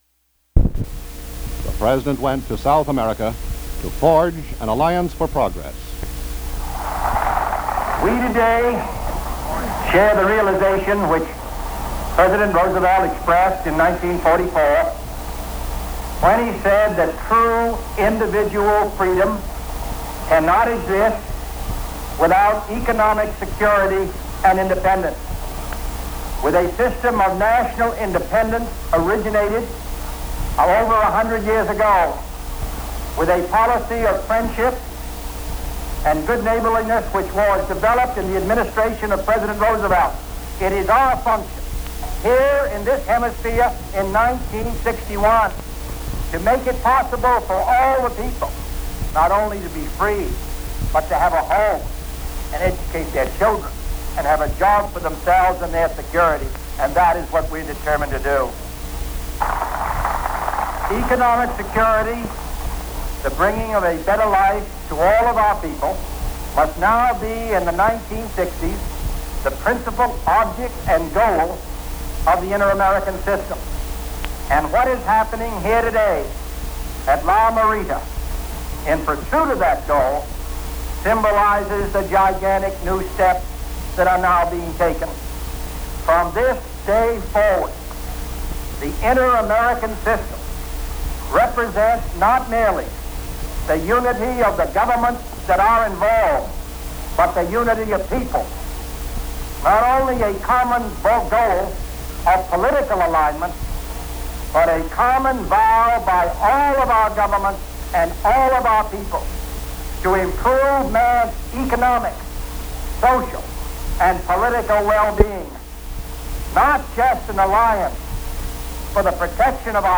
U.S. President John F. Kennedy addresses the nation